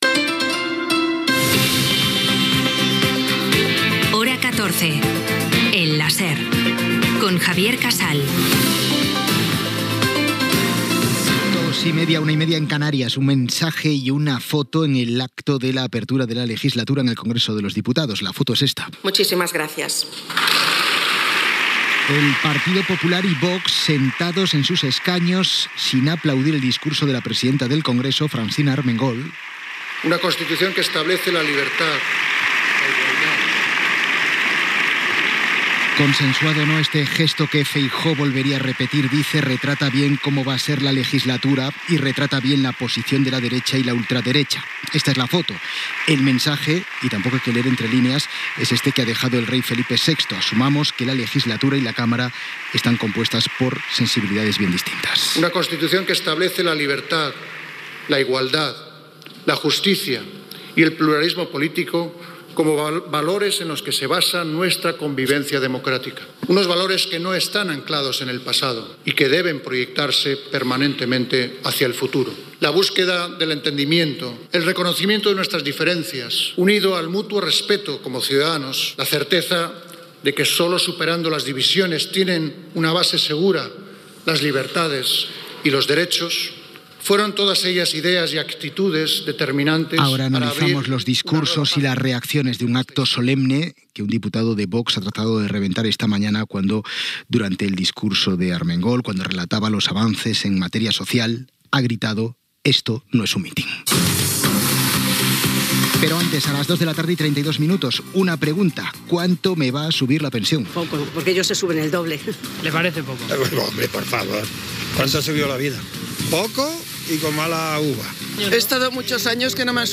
Careta del programa, hora, apertura de la legislatura al Congrés dels Diputats, les pensions, el subsidi d'atur, hora, previsió del creixement econòmic a Espanya, valoracions polítiques del discurs de la presidenta del Congrés de Diputats Frnacina Armengol , nous càrrecs a la directiva del Partido Popular, publicitat, indicatiu del programa Gènere radiofònic Informatiu